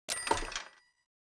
Buy Sound.wav